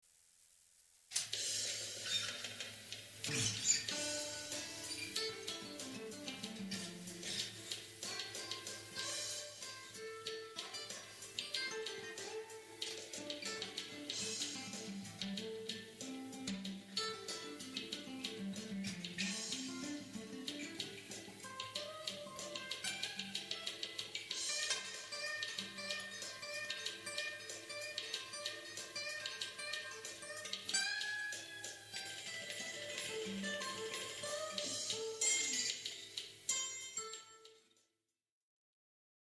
А так, да, теппинг и бенды не так как надо звучат